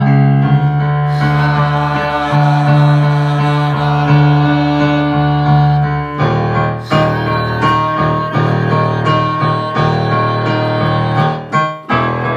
「ラ行」の「ラ」を、同じ音で続けて発音します。
最初は息から始まって「Ha-lalala・・・」と発声練習します。